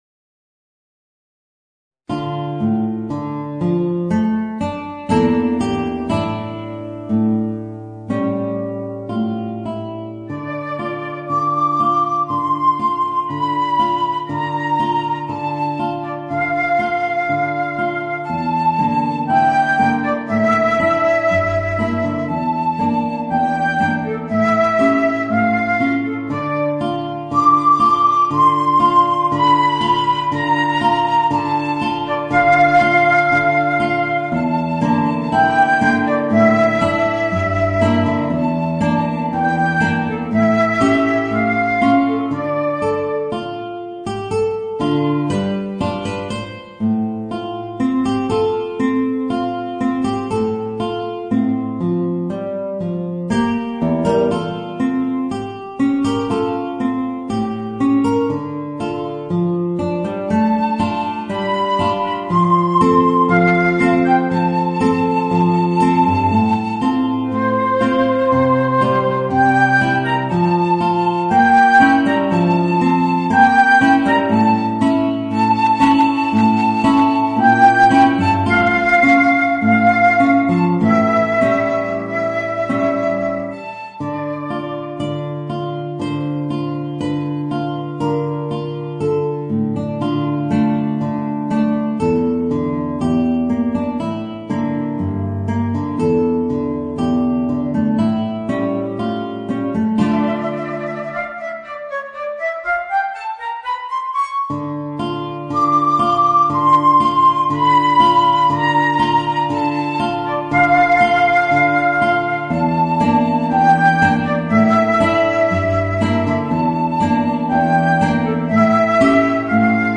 Voicing: Flute and Guitar